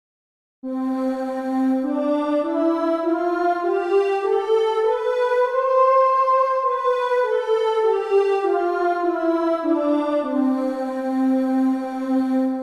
049～056：アンサンブル・グループ
アンサンブル・グループには、名前のとおり「アンサンブル（合奏）」された音色が集められています。
いくつかの弦楽器を同時に演奏した際の音色、聖歌隊で「アー」と合唱されたような音色です。